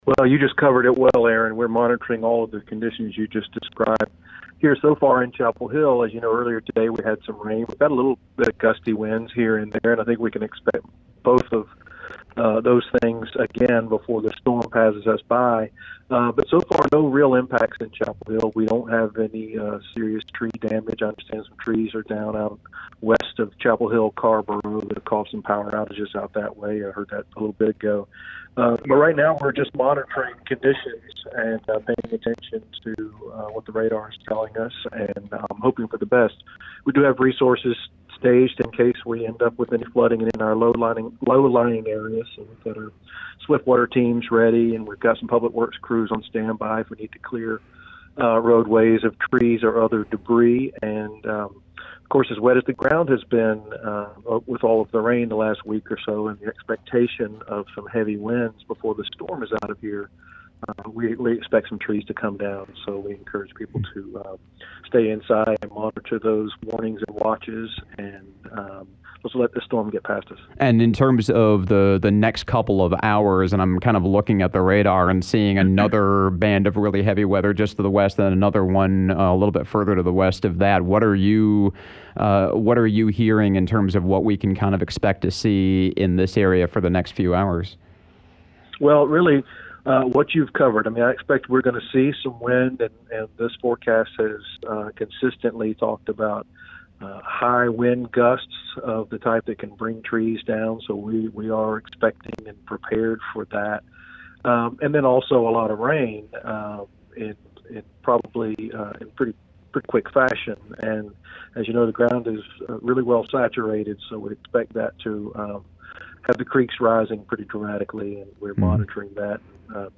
Chapel Hill Police Chief Chris Blue: